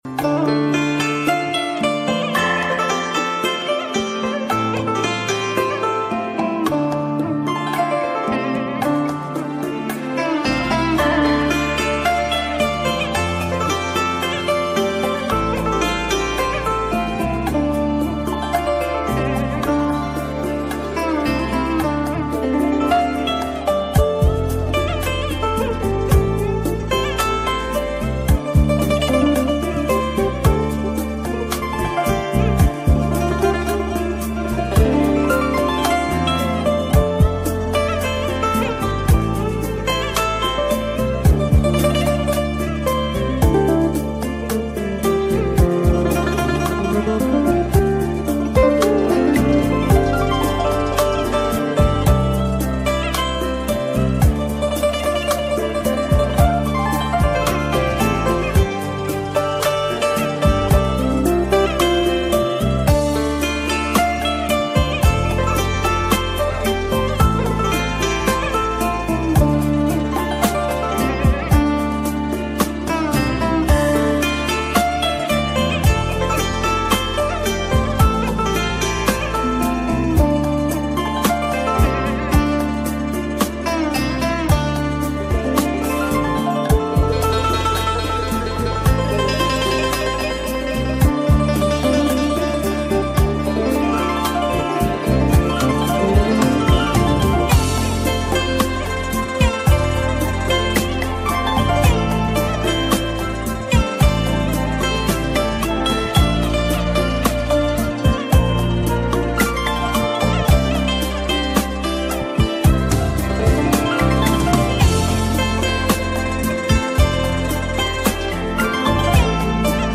đàn tranh